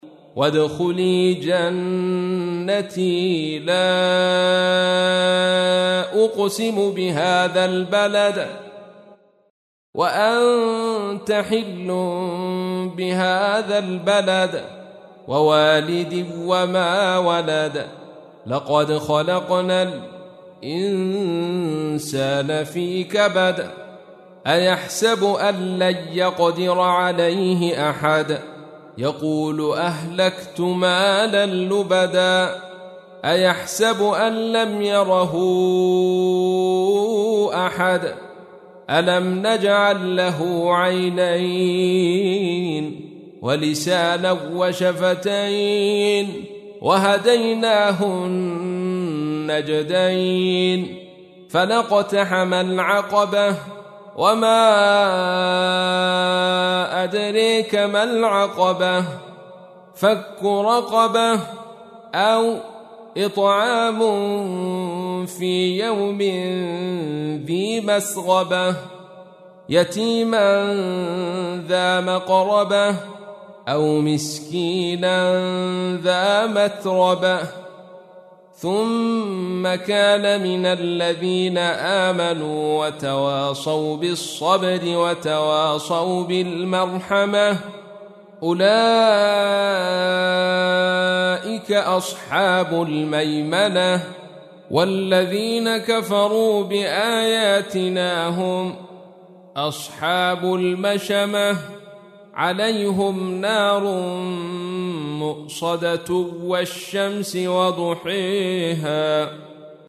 تحميل : 90. سورة البلد / القارئ عبد الرشيد صوفي / القرآن الكريم / موقع يا حسين